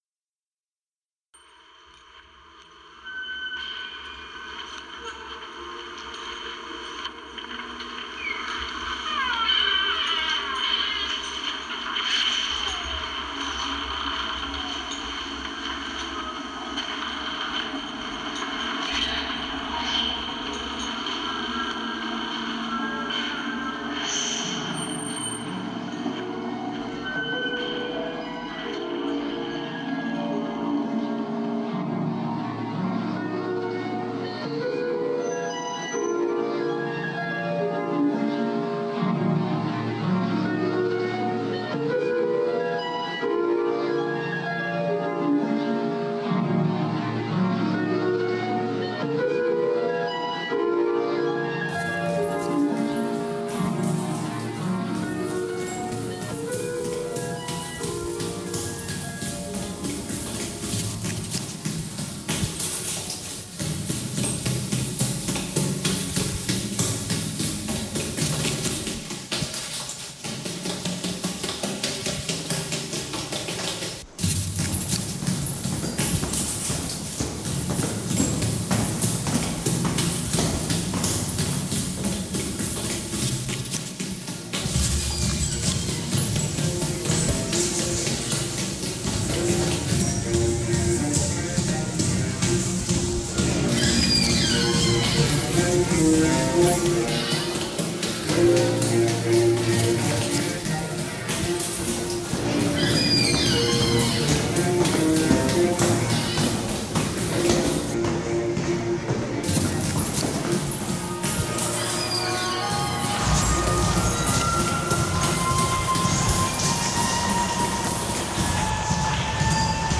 overhearing rehearsals and music as we go.